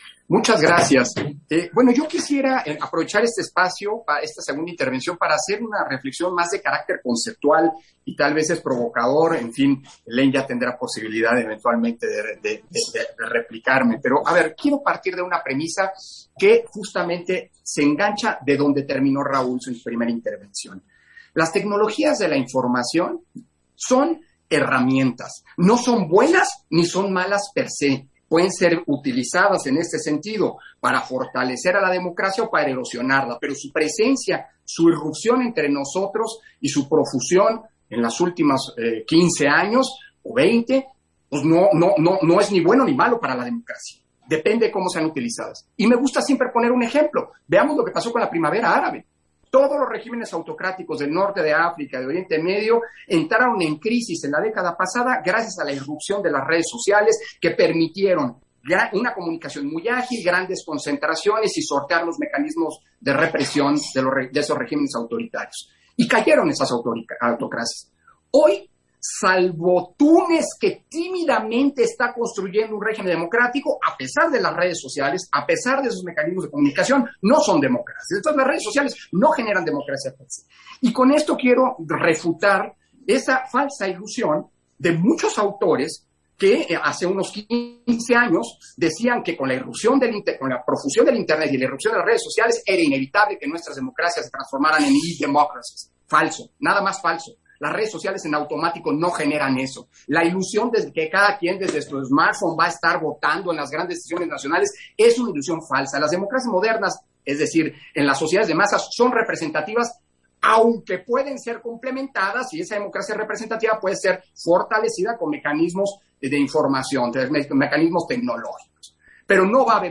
Intervención de Lorenzo Córdova, en el panel Democracia Digital: Inclusión y participación política, del evento virtual 5G